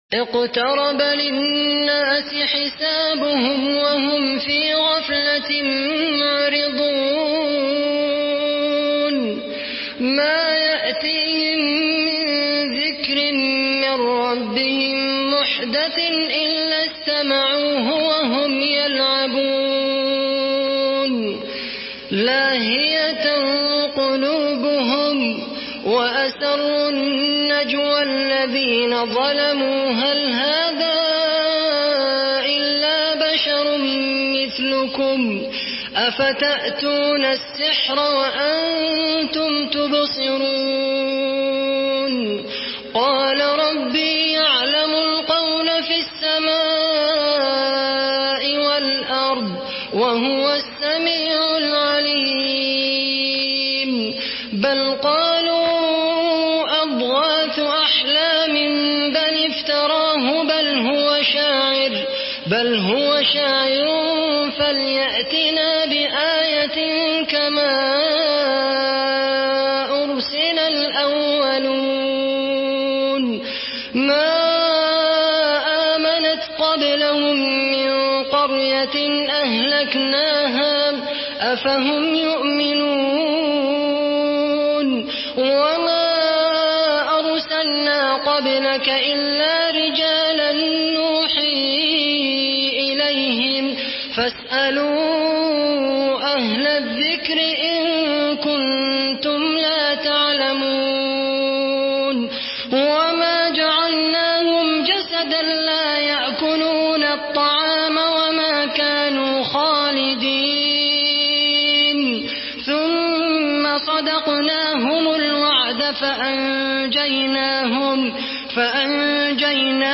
Surah Enbiya MP3 by Khaled Al Qahtani in Hafs An Asim narration.
Murattal Hafs An Asim